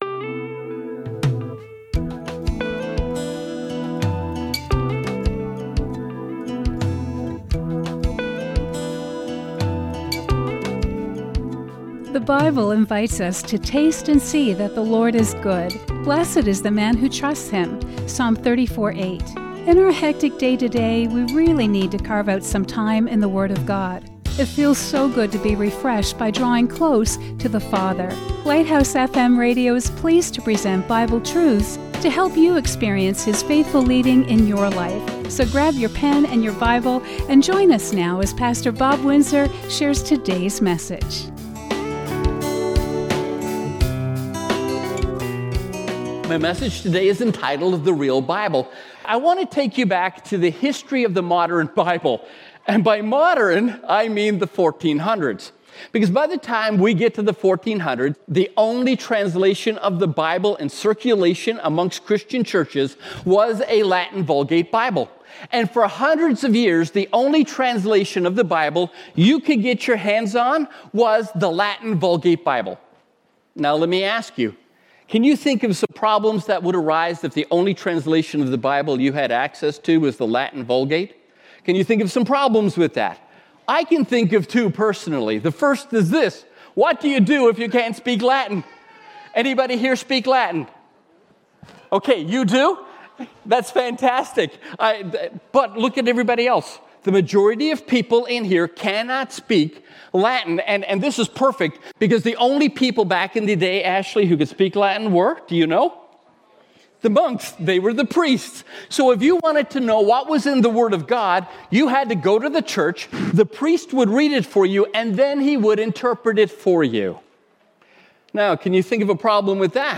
Inspiring sermons presented by 5 pastors